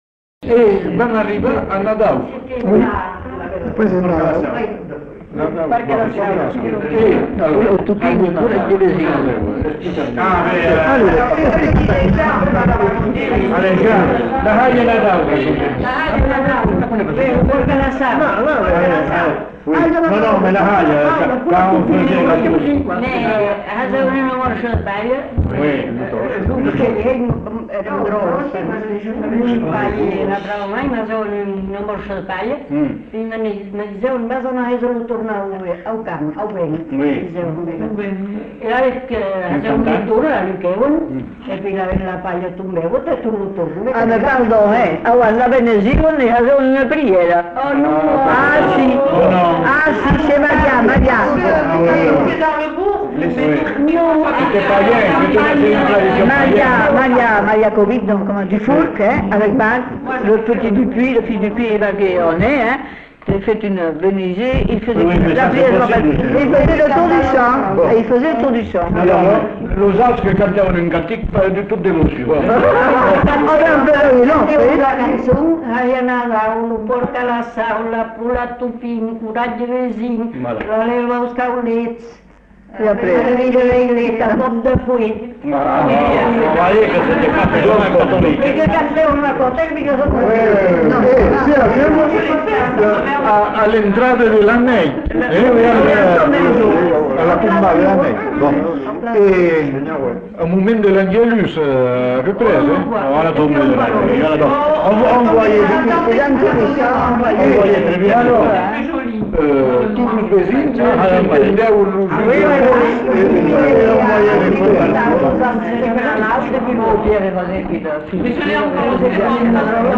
Lieu : Uzeste
Genre : témoignage thématique
Ecouter-voir : archives sonores en ligne